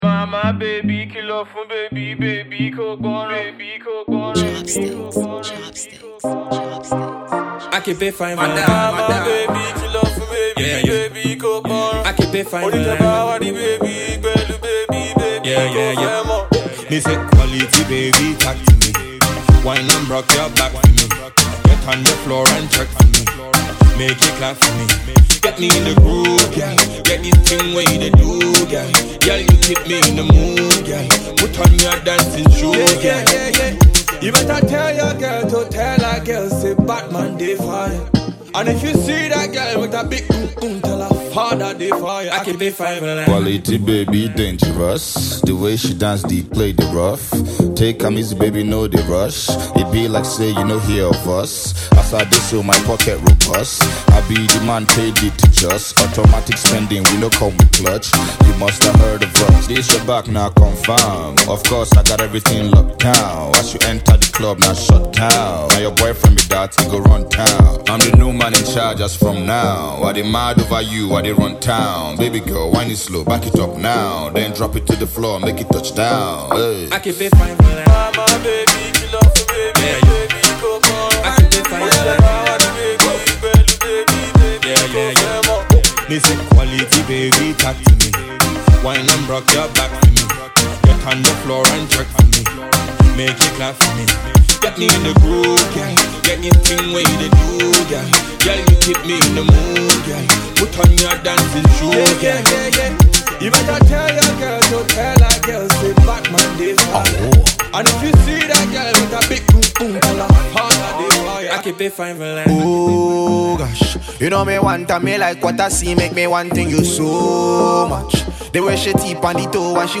is a feel good raggaeton tinged afro-pop song